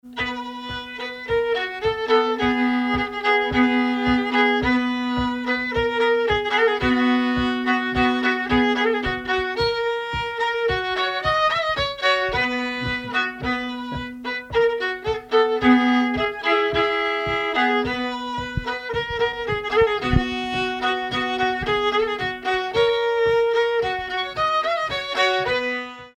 Saint-Nicolas-la-Chapelle
circonstance : bal, dancerie
Pièce musicale inédite